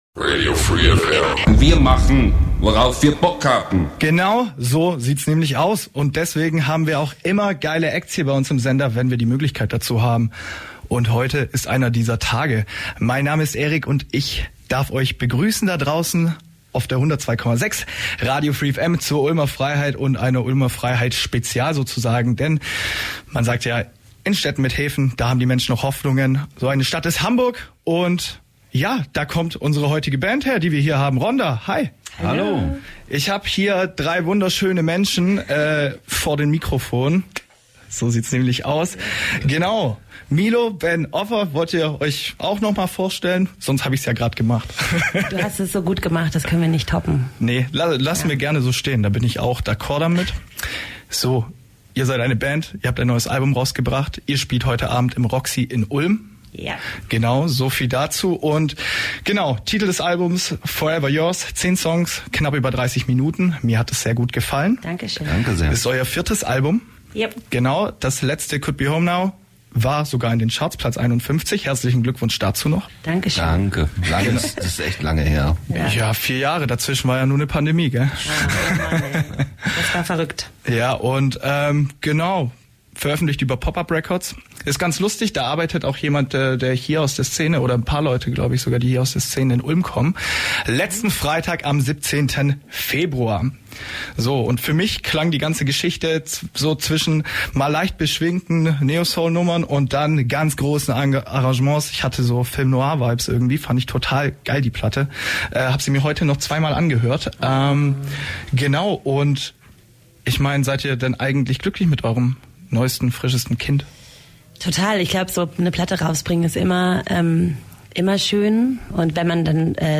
Ganz großes Kino - Die Band Rhonda im Interview